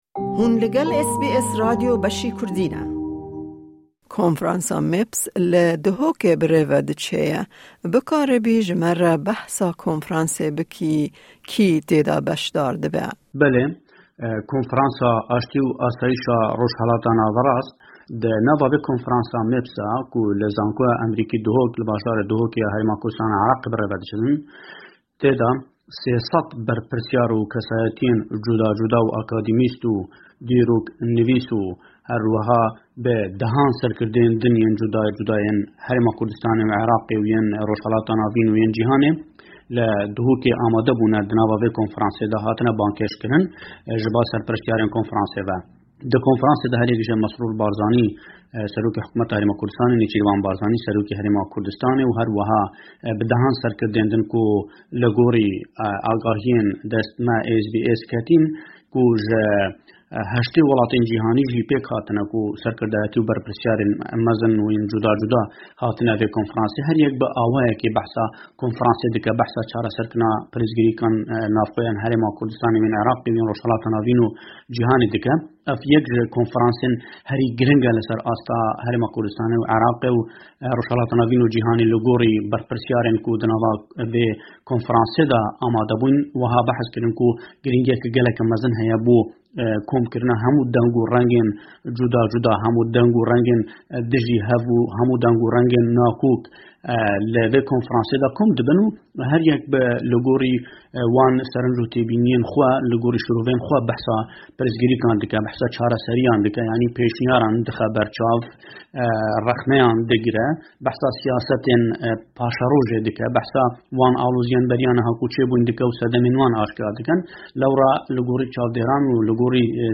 Herweha babetên din jî di raporta ji Hewlêrê de hene.